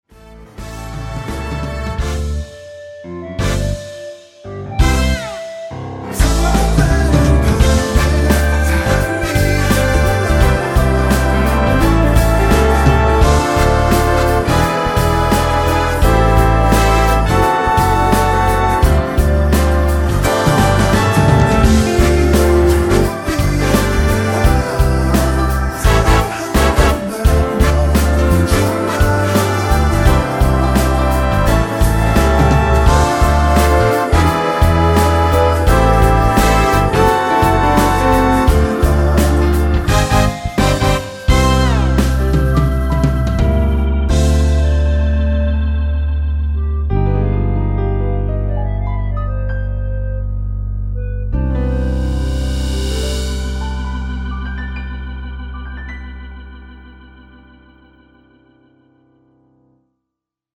2분56초 부터 10초 정도 보컬 더블링 된 부분은 없습니다.(미리듣기 확인)
원키에서(-2)내린 멜로디와 코러스 포함된 MR입니다.
앞부분30초, 뒷부분30초씩 편집해서 올려 드리고 있습니다.
중간에 음이 끈어지고 다시 나오는 이유는